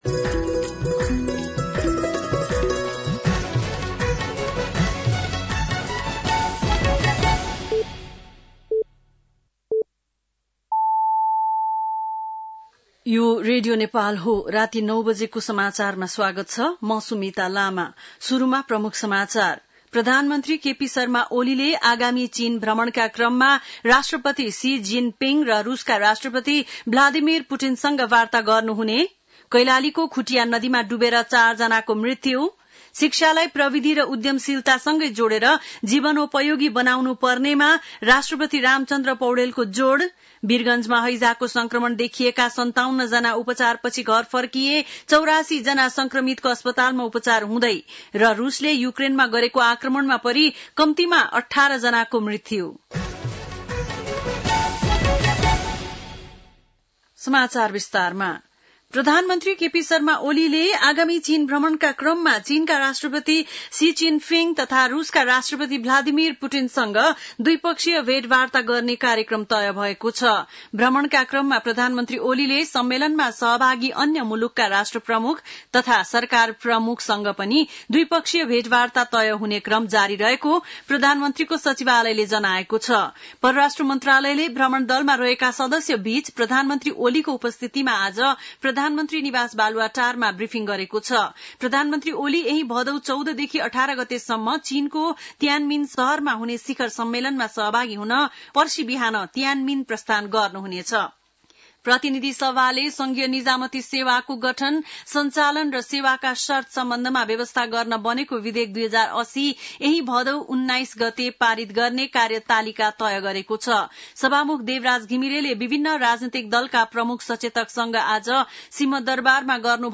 बेलुकी ९ बजेको नेपाली समाचार : १२ भदौ , २०८२
9-PM-Nepali-NEWS-5-12.mp3